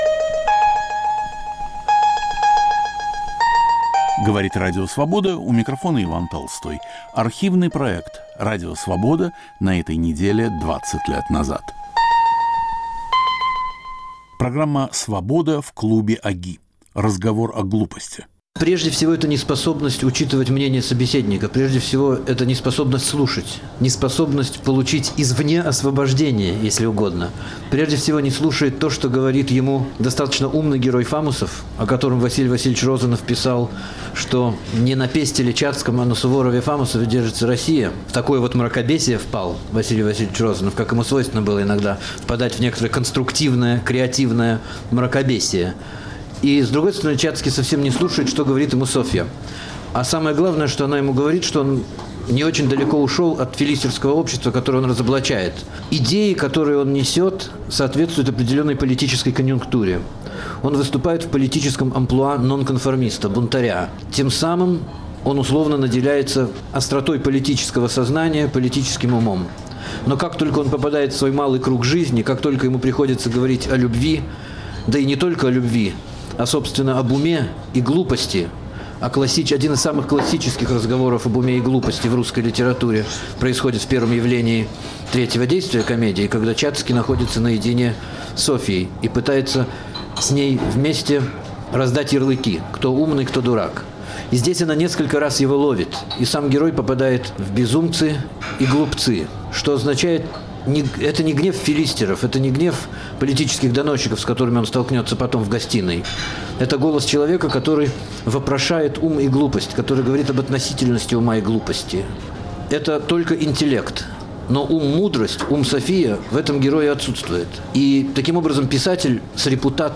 "Свобода" в клубе ОГИ. Разговор о глупости